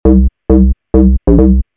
Hard House Donk (Can also be used in slower house genres)
Donk-Bass.mp3